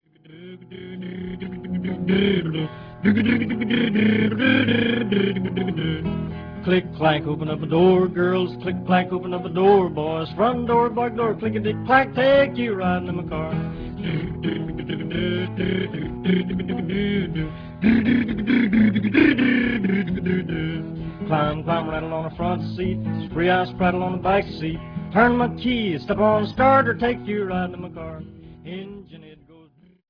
Recorded in New York, New York between 1944 and 1947.